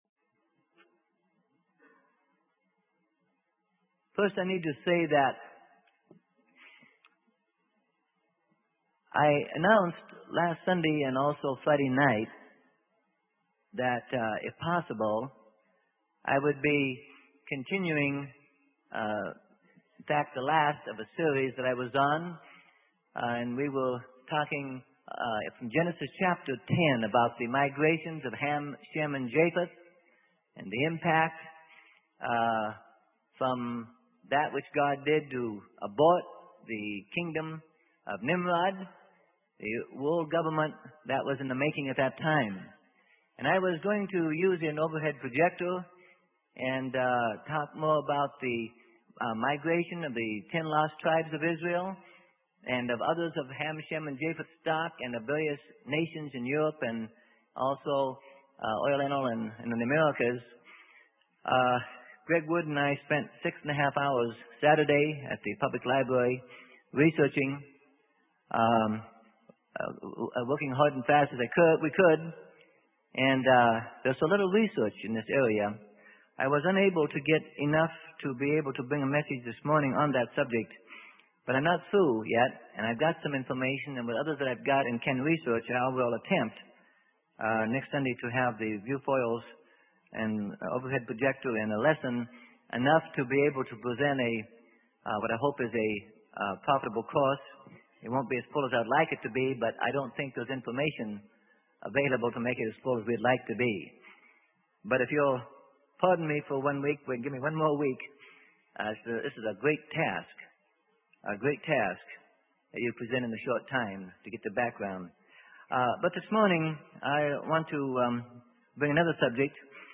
Sermon: The Ministry of the Priesthood: A Lesson for Believers - Freely Given Online Library